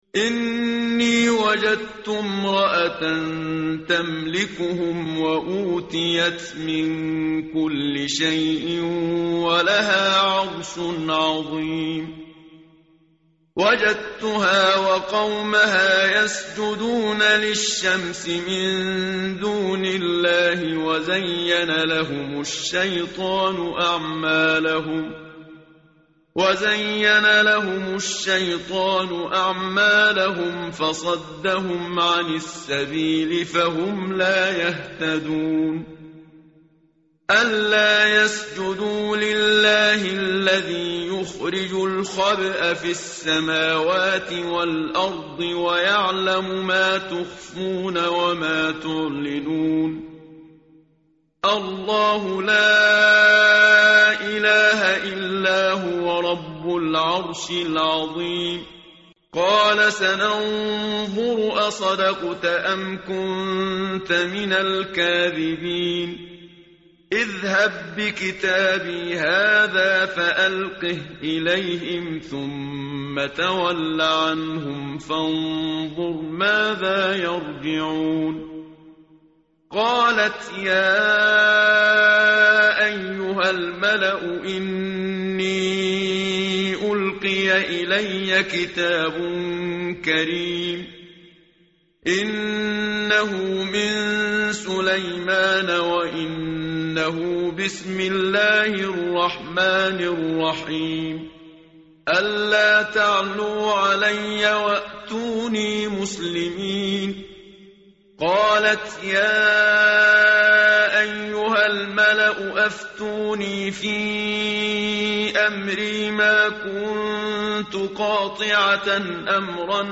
متن قرآن همراه باتلاوت قرآن و ترجمه
tartil_menshavi_page_379.mp3